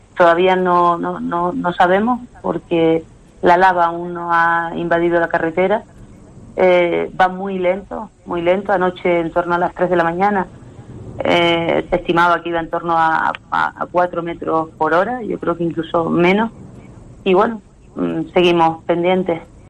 El lento avance de la lava ha dado una tregua a los habitantes del barrio de Todoque, en Los Llanos de Aridane, en la isla de La Palma. Noelía García, alcaldesa del municipio, ha avanzado en los micrófonos de COPE Canarias, que el centro del barrio de Todoque "aún no se ha visto afectado por la lava", algo eso sí, que no se descarta.